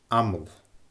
amall /aməL/